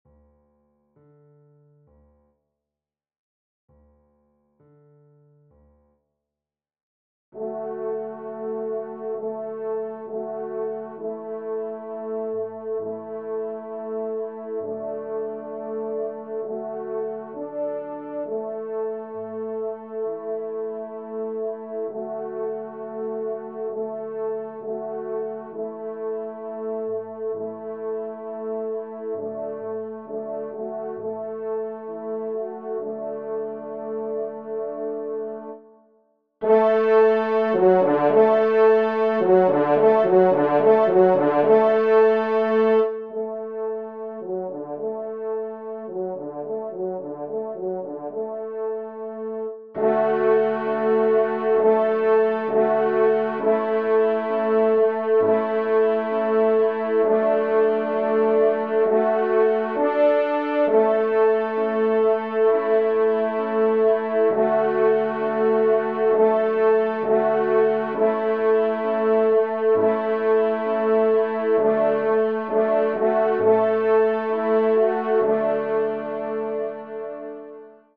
Genre :  Divertissement pour Trompe ou Cor et Piano
3e Trompe              4e Trompe